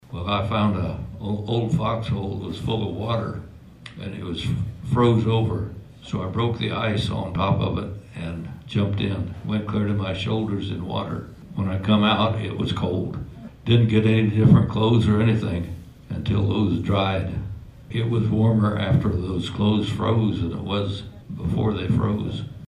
Stories were shared during a panel discussion Saturday at the American Legion.